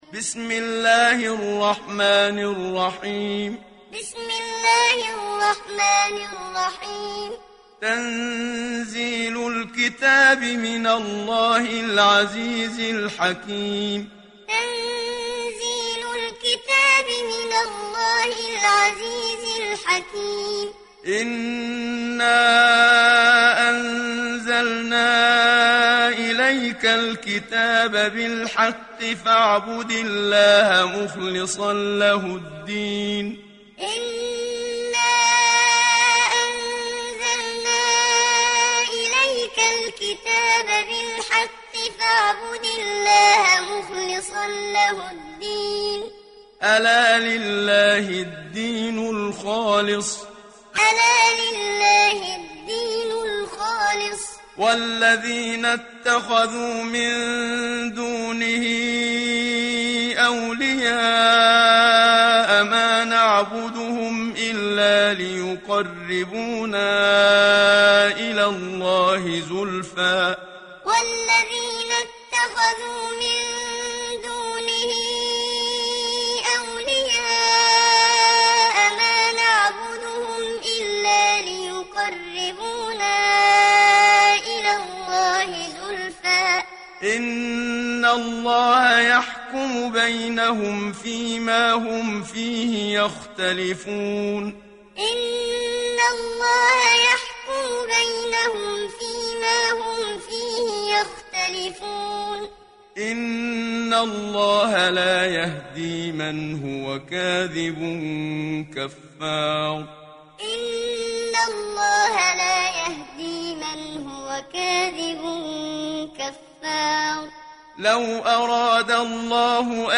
Muallim